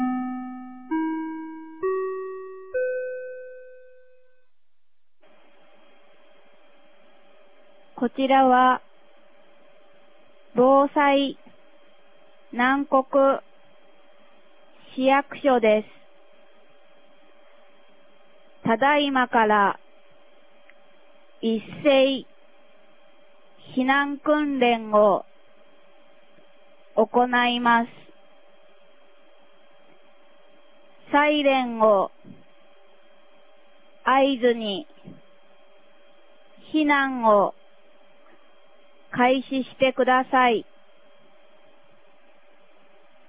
2021年11月07日 09時00分に、南国市より放送がありました。